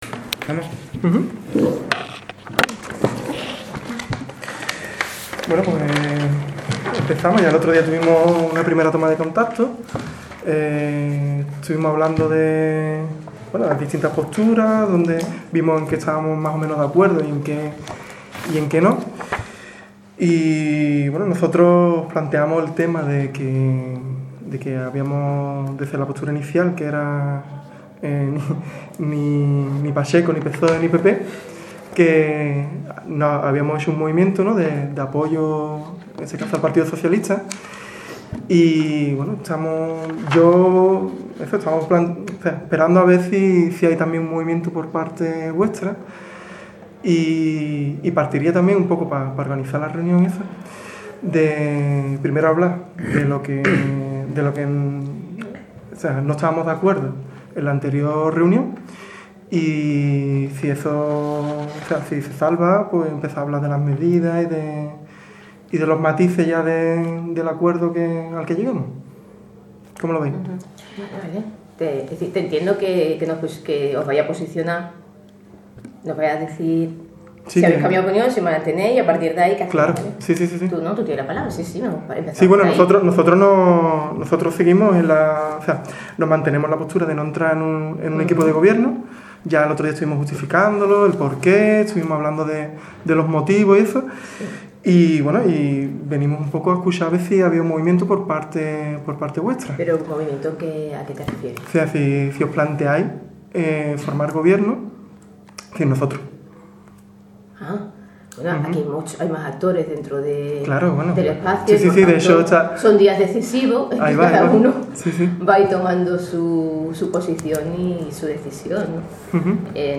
Audio de la segunda reunión con PSOE
Aquí os dejamos el audio completo con la reunión que hemos tenido hoy con el PSOE (la segunda)